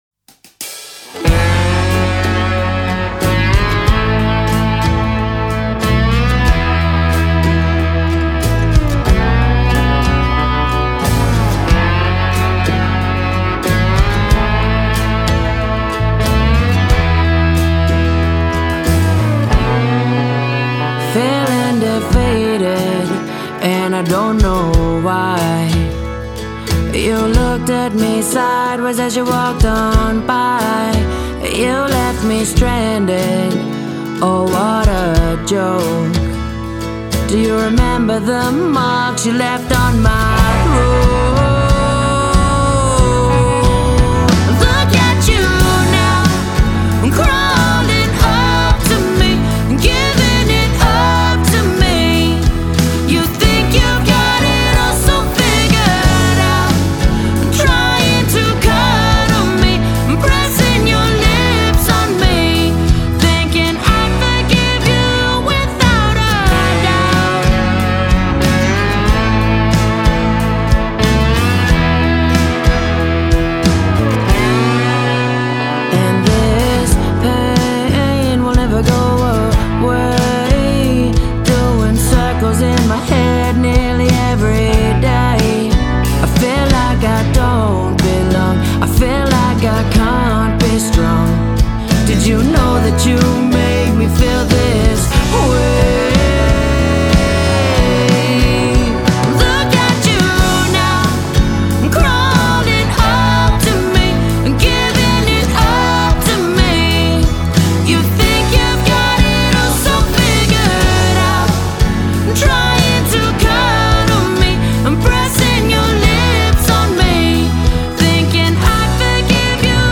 producing a new country heartbreak anthem.